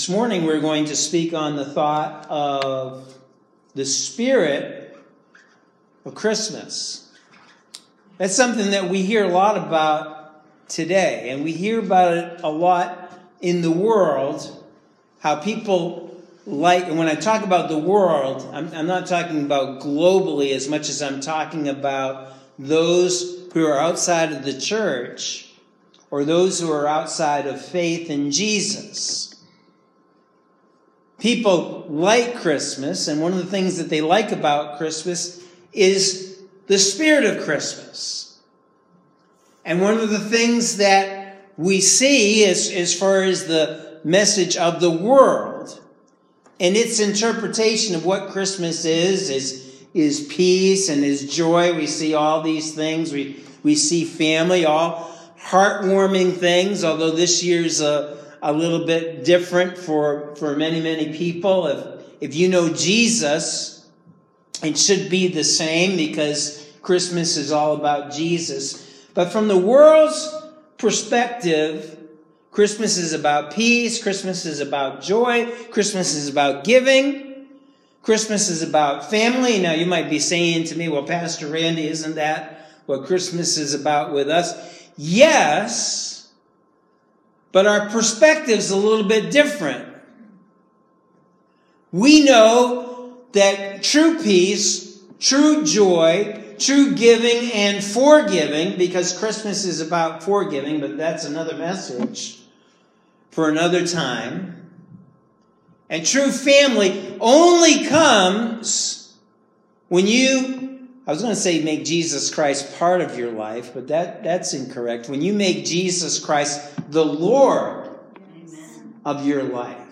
Sunday December 20th Sermon – The Spirit of Christmas – Norwich Assembly of God